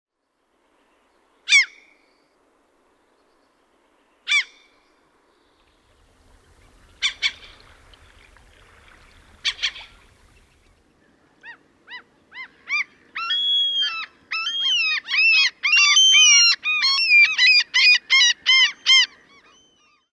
Kalalokki
Larus canus
Ääni: Riemukkaasti kiljahteleva.